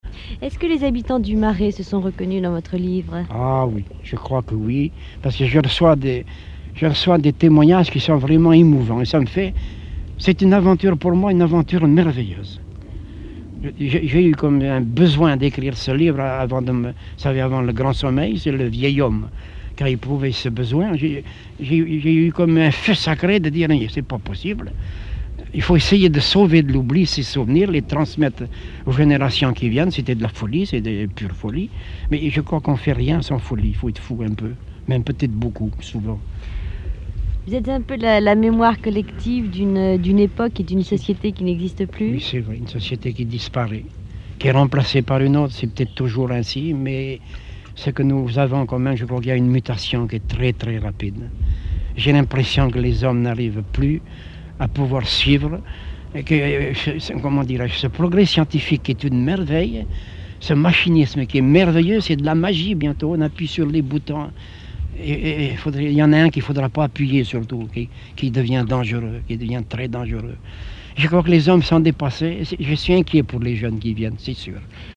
Emisson Départementale, sur Radio France Culture
Témoignage